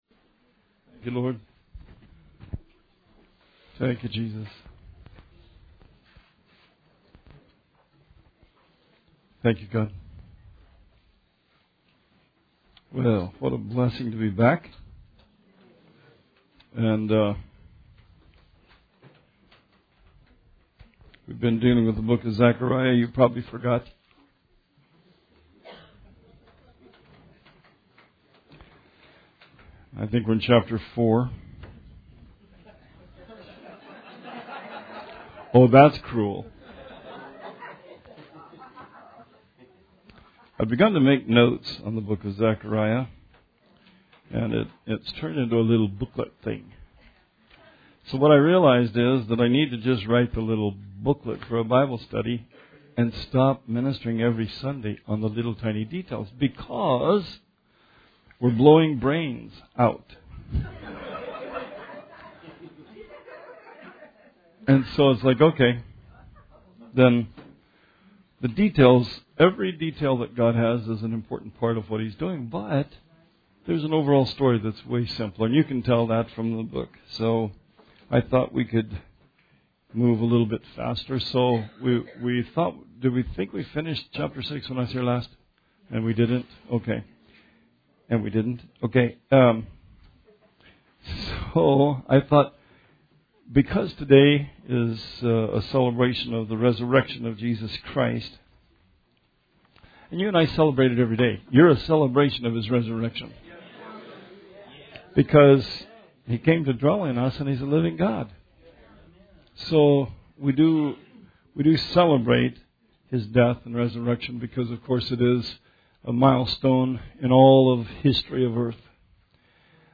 Sermon 4/1/18